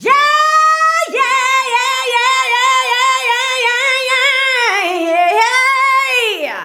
YEAHYEAH  -R.wav